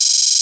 Gamer World Open Hat 4.wav